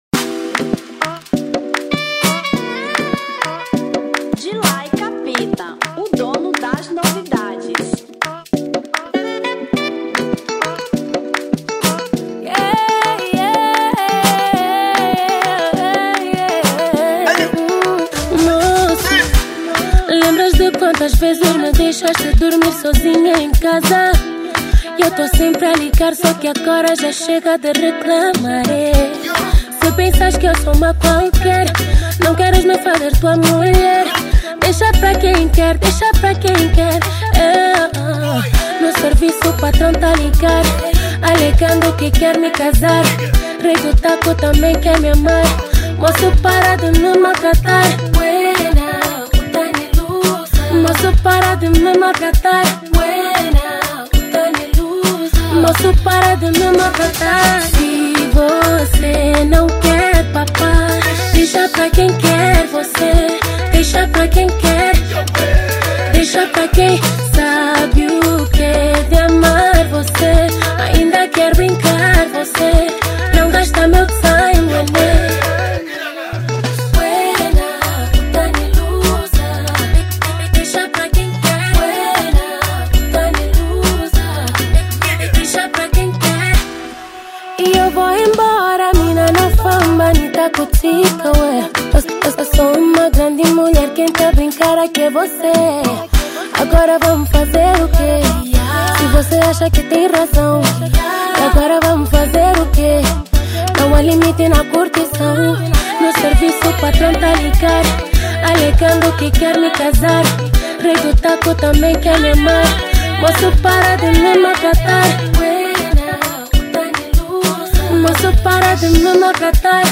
R&B 2025